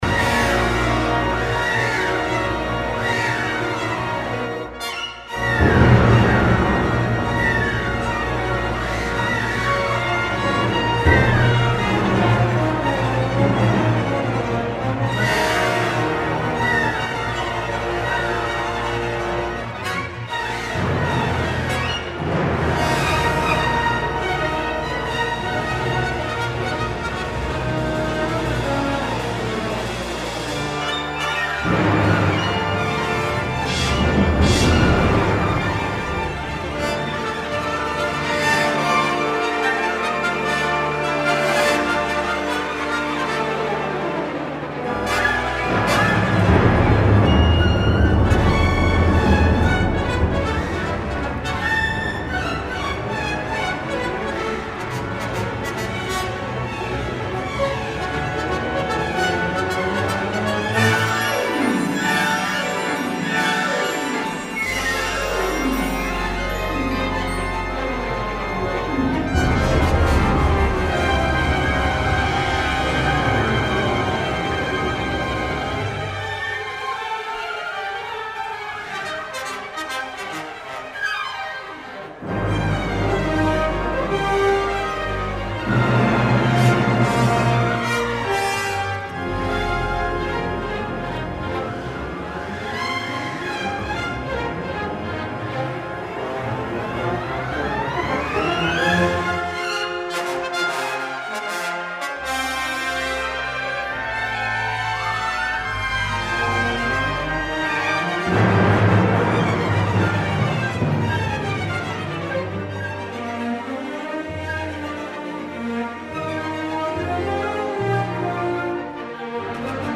dramatic tone poem
This concert was recorded and has now been released.